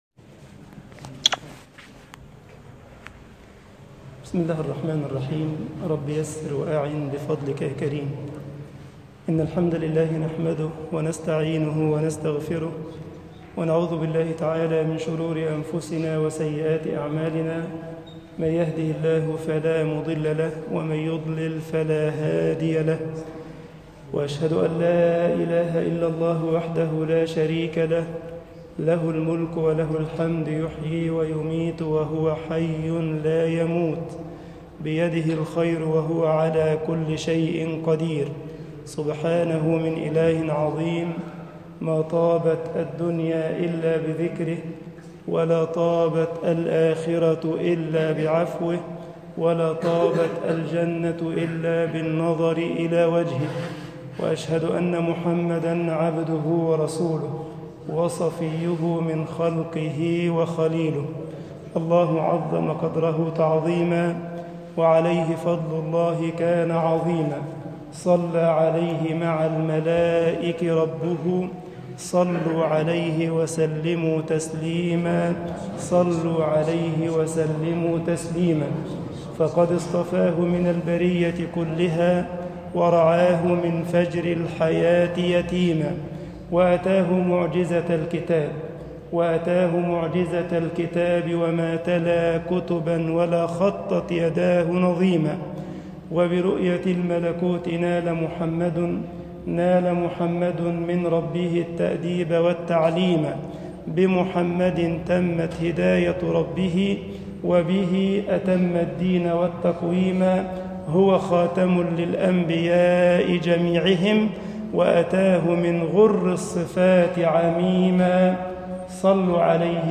طاعة الرسول صلي الله عليه وسلم - محاضرة
Taatu arrasul salla Allahu aliah wassalam - muhadharah.mp3